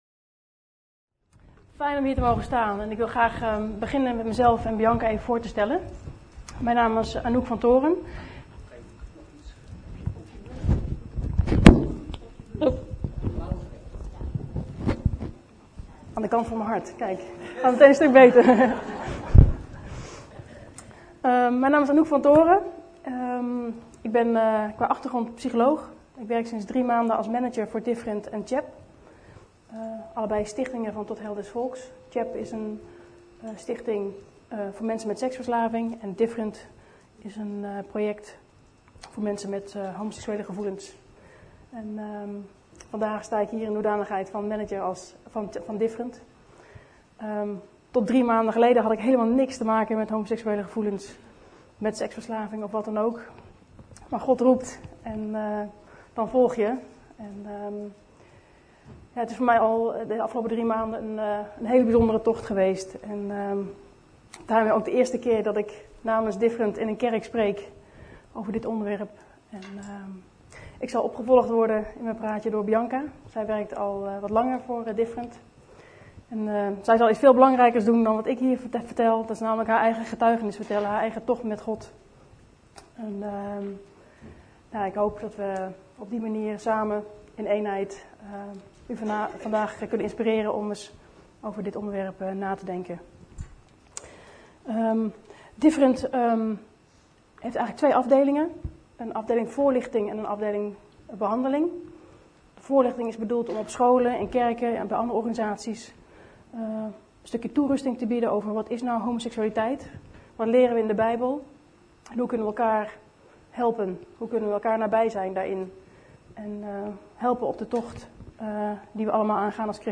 Overzicht van preken van Preken op Christengemeente Bethel